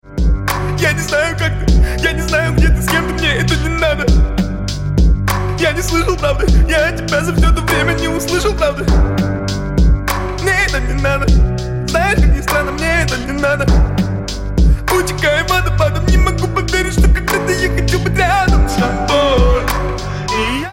громкие
русский рэп
странные